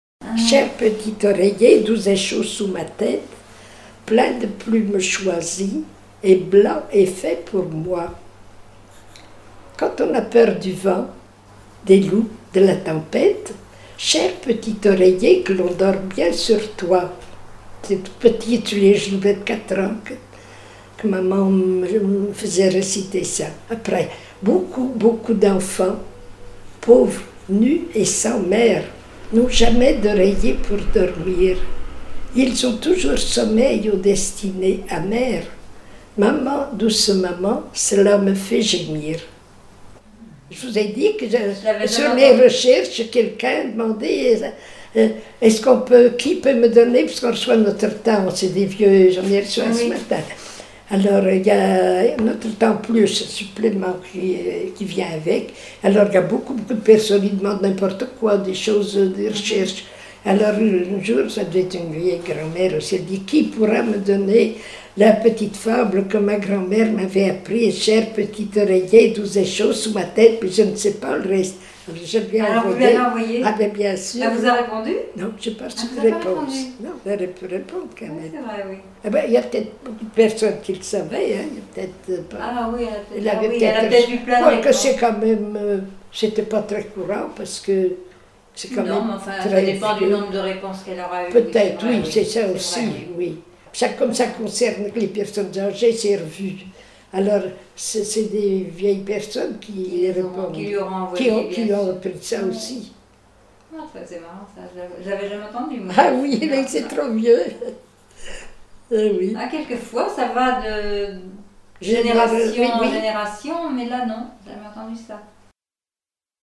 enfantine : berceuse
Enquête Arexcpo en Vendée-Pays Sud-Vendée
Pièce musicale inédite